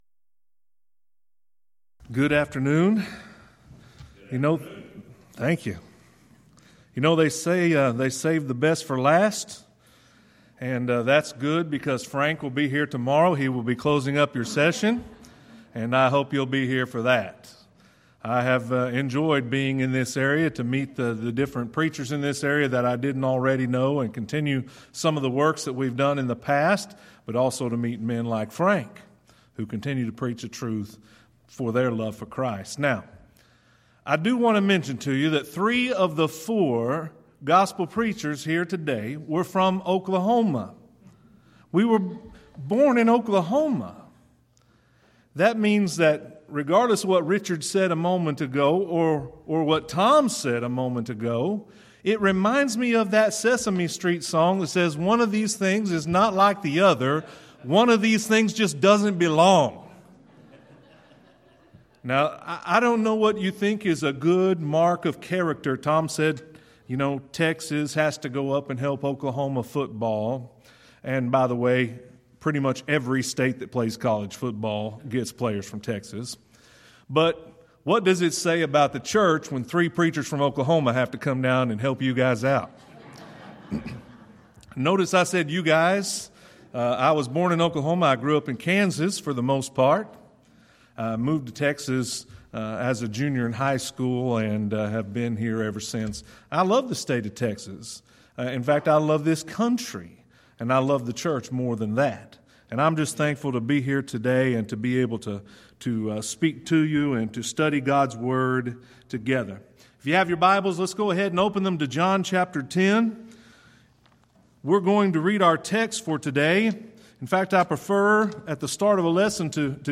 Back to the Bible Lectures